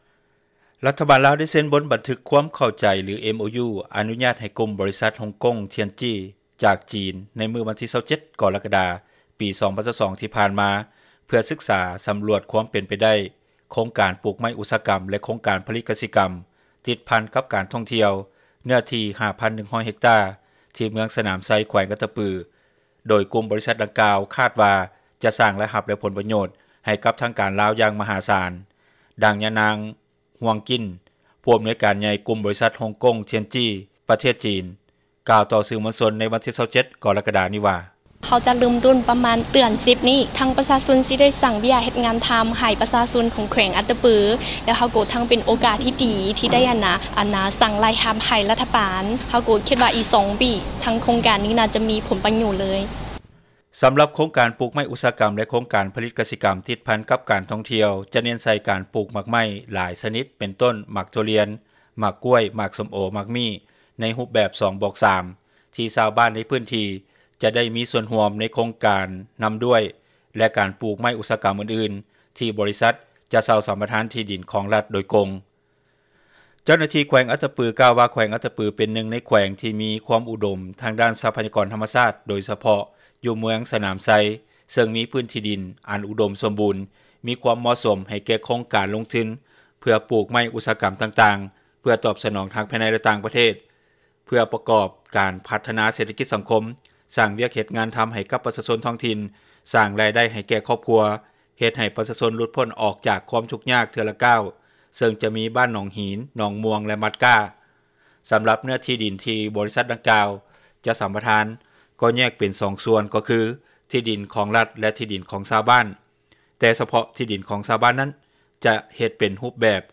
ດັ່ງຊາວບ້ານ ໃນເມືອງສນາມໄຊ ແຂວງອັດຕະປື ກ່າວຕໍ່ວິທຍຸເອເຊັຽເສຣີ ໃນວັນທີ 05 ສິງຫານີ້ວ່າ:
ດັ່ງຊາວບ້ານ ເມືອງສນາມໄຊ ແຂວງອັດຕະປື ອີກທ່ານນຶ່ງ ກ່າວຕໍ່ວິທຍຸເອເຊັຽເສຣີ ໃນວັນທີ 05 ສິງຫານີ້ວ່າ: